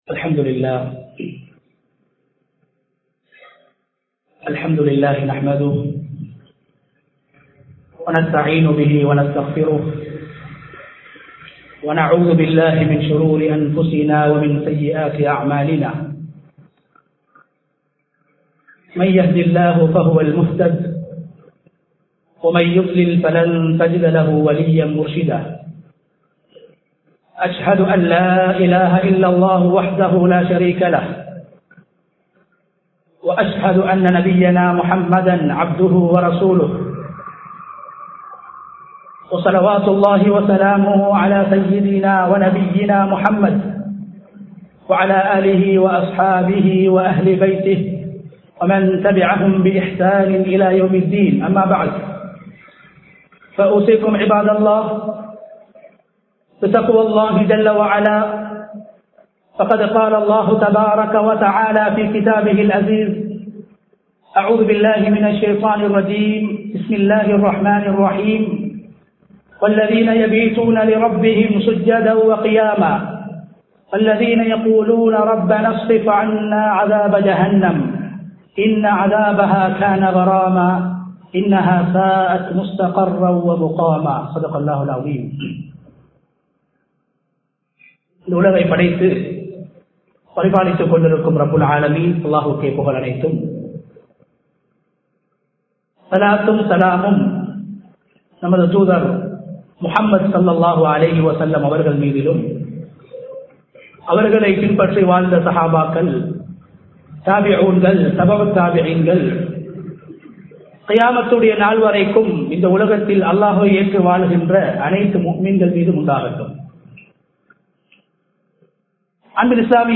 ரமழானும் இறுதி முடிவும் | Audio Bayans | All Ceylon Muslim Youth Community | Addalaichenai
Ambagamuwa Jumua Masjith